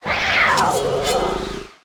Cri de Baojian dans Pokémon Écarlate et Violet.